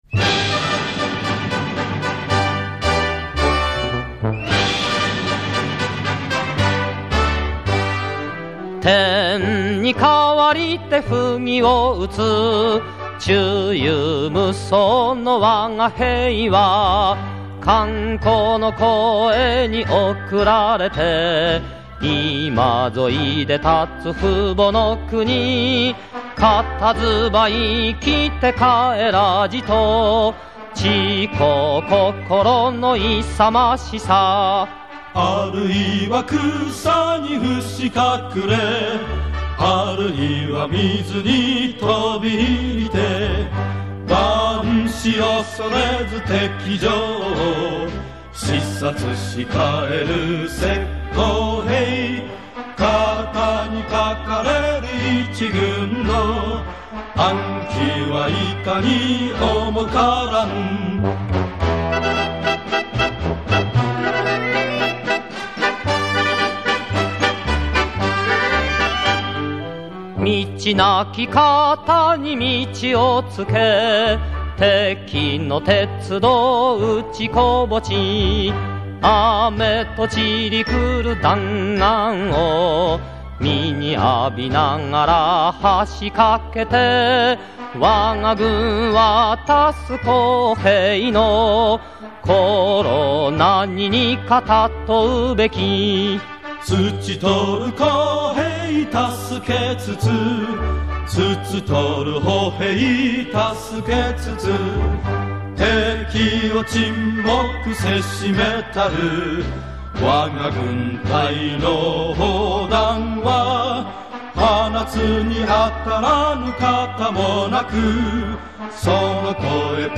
У японцев довольно красивая музыка (марши) времен войны.